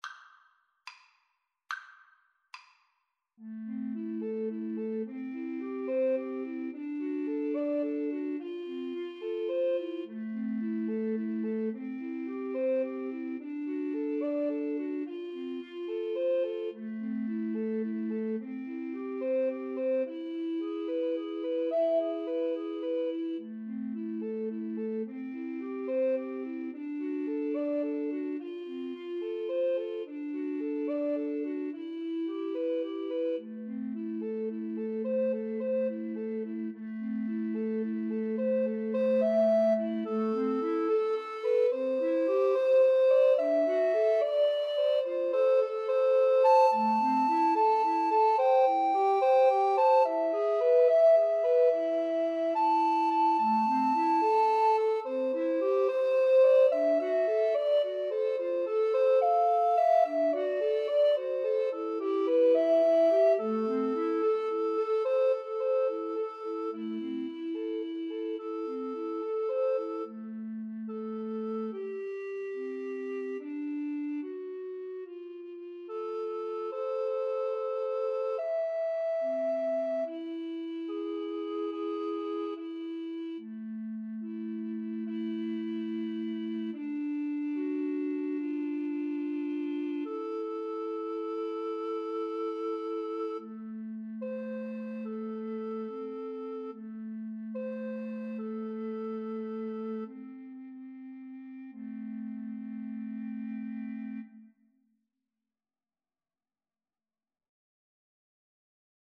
Free Sheet music for Recorder Trio
Alto RecorderTenor RecorderBass Recorder
A minor (Sounding Pitch) (View more A minor Music for Recorder Trio )
Gently . = c.72
6/8 (View more 6/8 Music)
Recorder Trio  (View more Intermediate Recorder Trio Music)
Pop (View more Pop Recorder Trio Music)